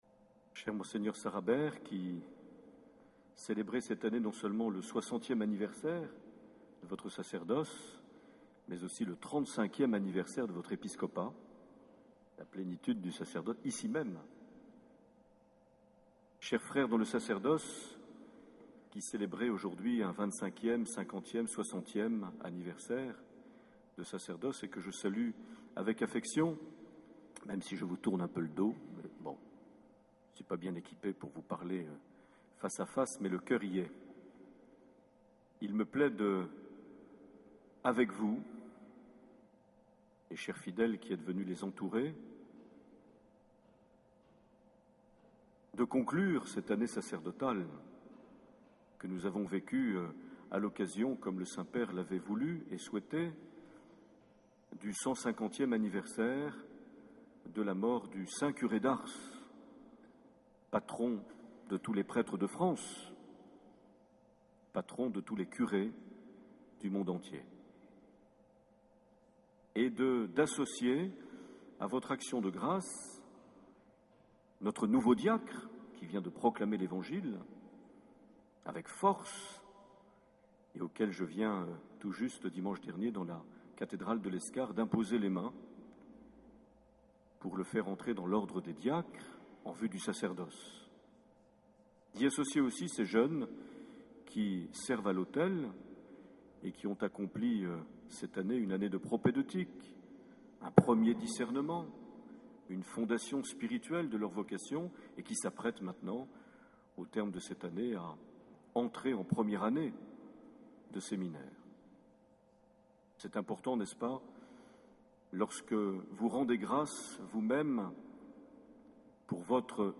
25 juin 2010 - Cathédrale de Bayonne - Messe d'action de grâces avec les prêtres jubilaires
Accueil \ Emissions \ Vie de l’Eglise \ Evêque \ Les Homélies \ 25 juin 2010 - Cathédrale de Bayonne - Messe d’action de grâces avec les (...)
Une émission présentée par Monseigneur Marc Aillet